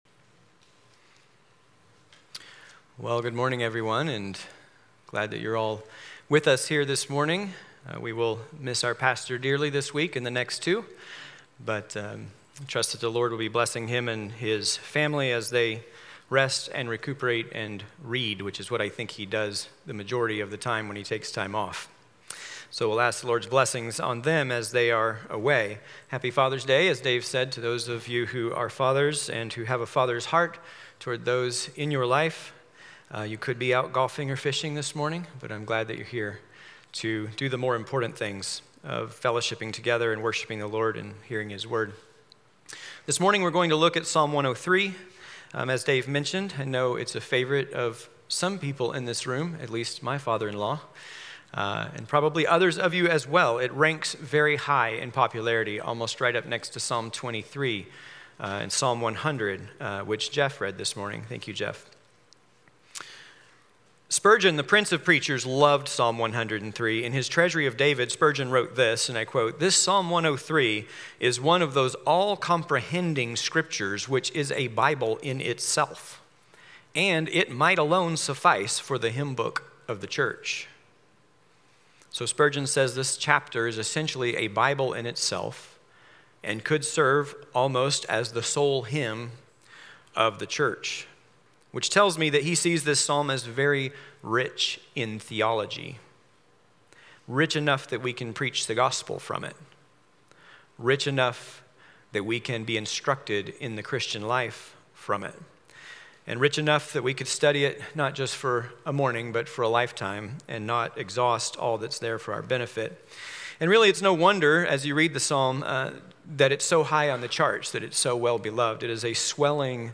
Passage: Psalm 103 Service Type: Morning Worship Service « Lesson 3